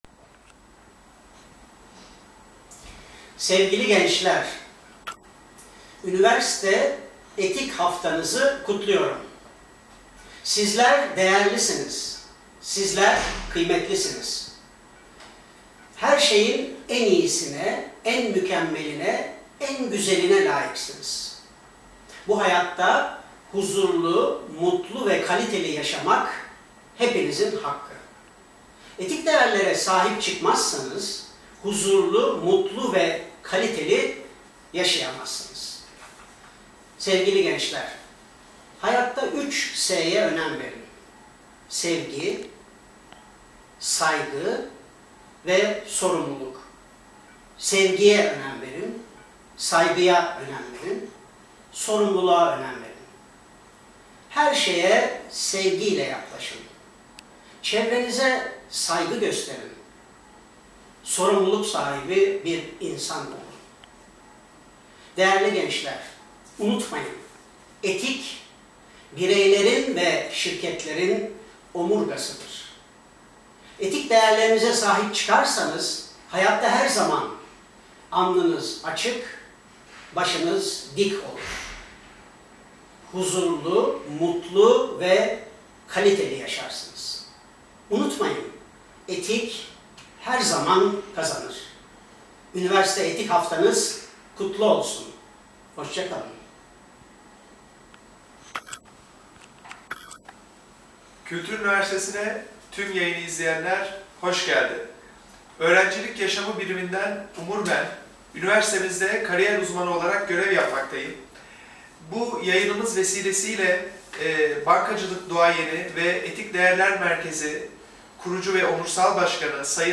Periscope'dan t�m K�lt�r �niversitesi ��rencilerine canl� yay�n yap�laca��n� s�yledi.